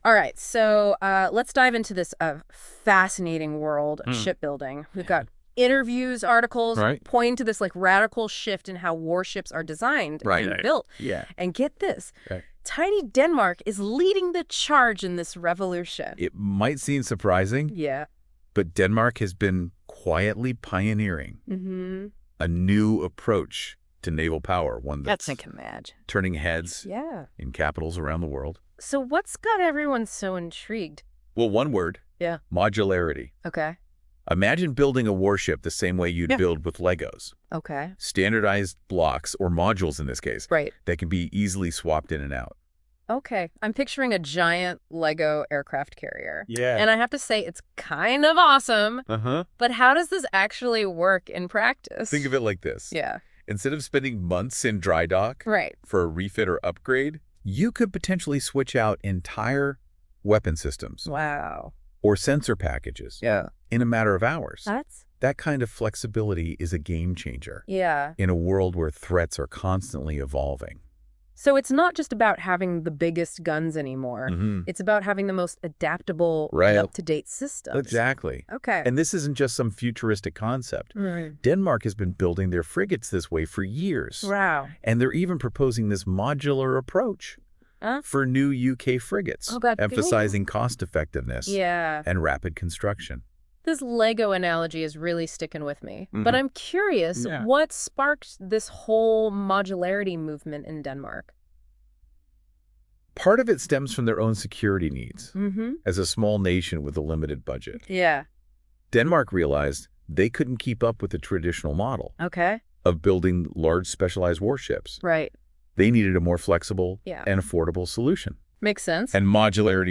In a Deep Dive podcast based on NotebookLM, this report is discussed.